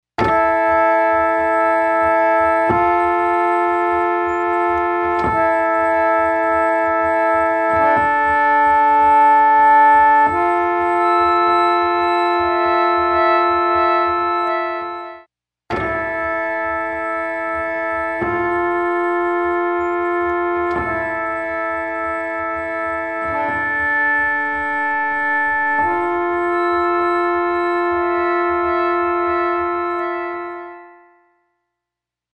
Dieser soll unsere Sounds mit einem analogen nachgemodelten Bucket Brigade (BBD) Reverb versehen.
Das Resultat ist „düster“, „fett“ und erweitert sogar Monosignale im Stereofeld.
pump_organ.mp3